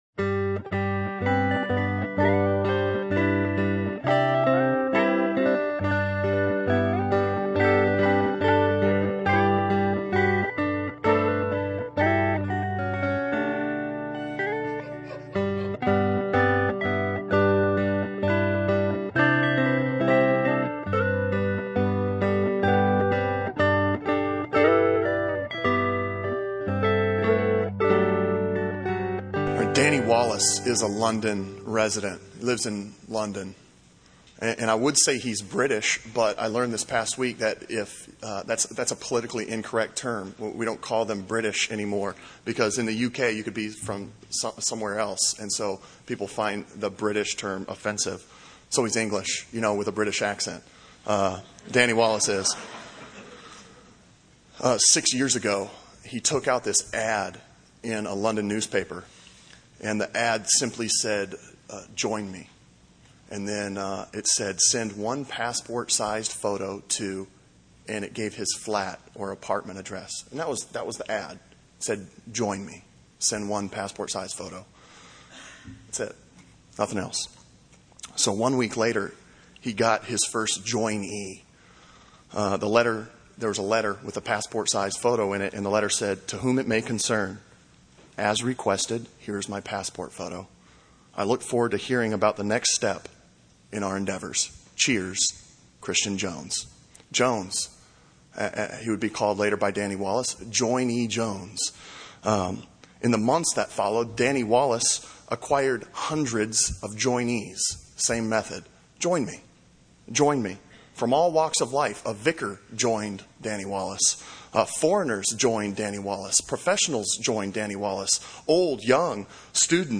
« Back to sermons page A Personal Invitation to Die Sermon from November 23